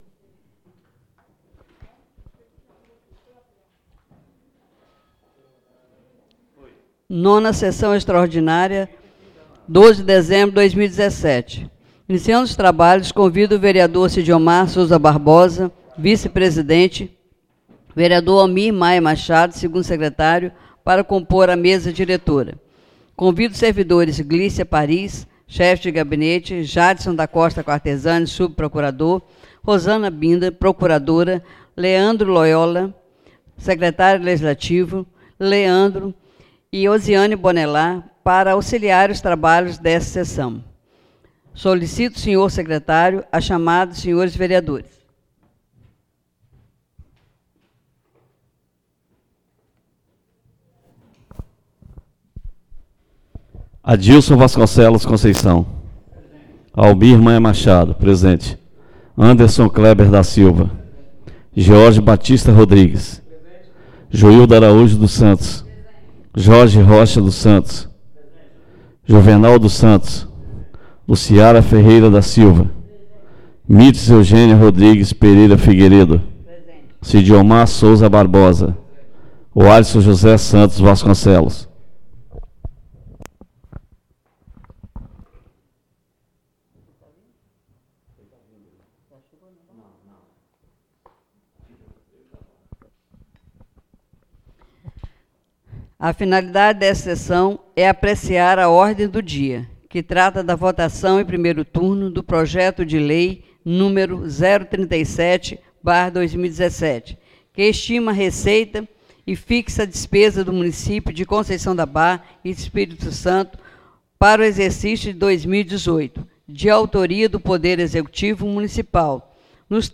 9ª (NONA) SESSÃO EXTRAORDINÁRIA DIA 12 DE DEZEMBRO DE 2017 SEDE